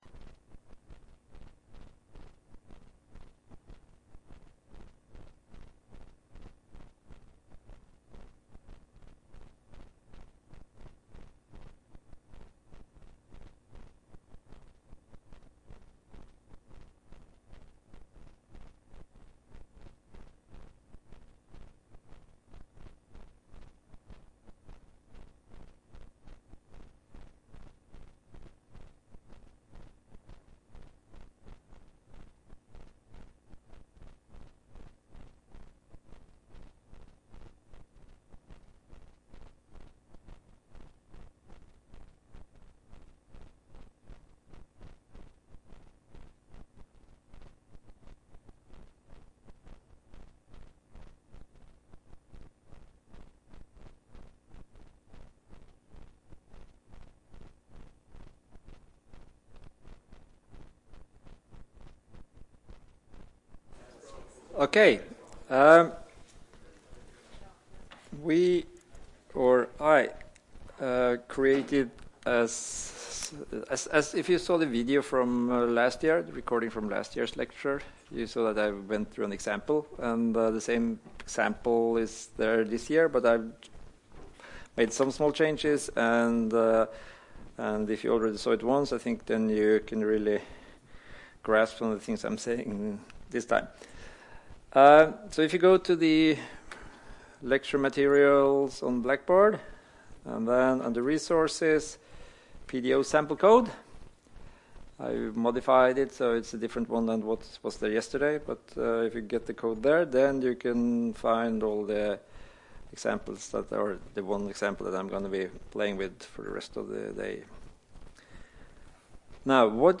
Rom: Smaragd 1 (S206)